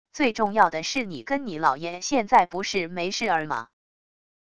最重要的是你跟你姥爷现在不是没事儿吗wav音频生成系统WAV Audio Player